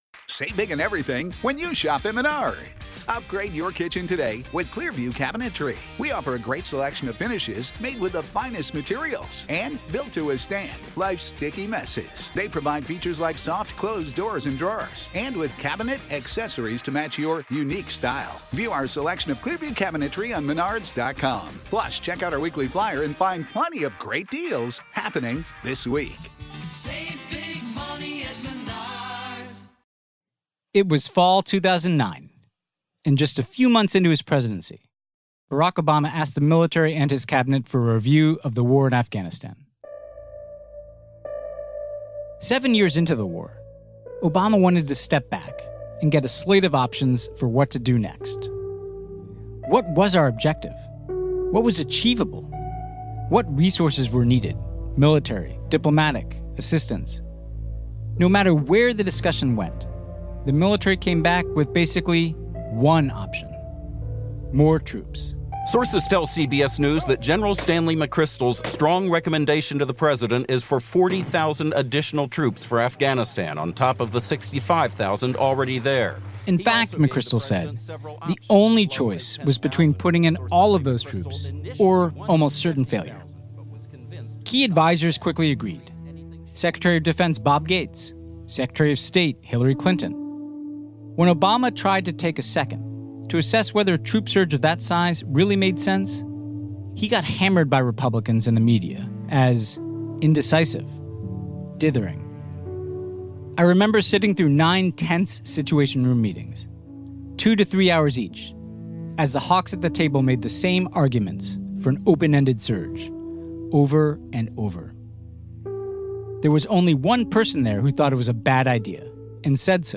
In a wide-ranging interview, host Ben Rhodes talks to Jake Sullivan, senior policy advisor to Vice President Joe Biden, about how the US can begin the work of restoring our standing and addressing the political plagues discussed in this podcast series.